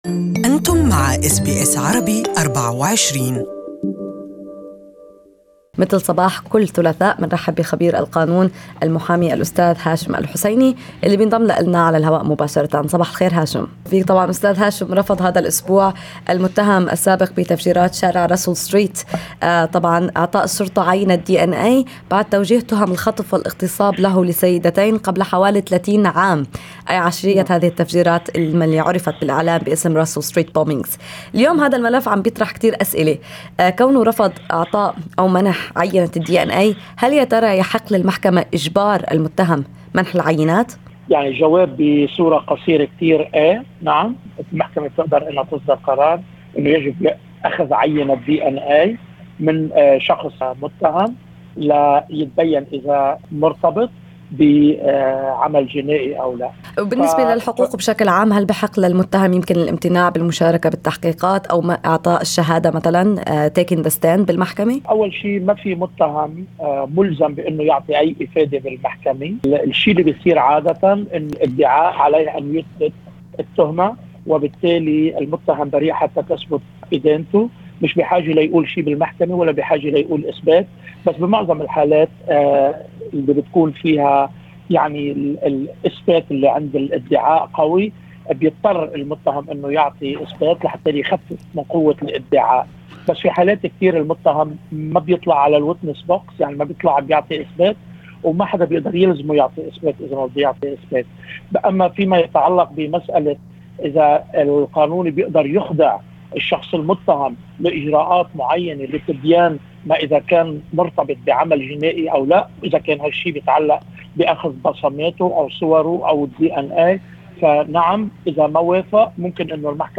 المزيد من التفاصيل في اللقاء الصوتي أعلاه